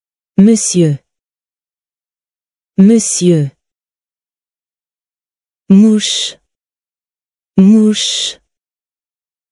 monsieur は最後の母音までしっかりと読むことが大切です。
[ムスィウー] または [ムスィユー] と読むと近いとおもいます。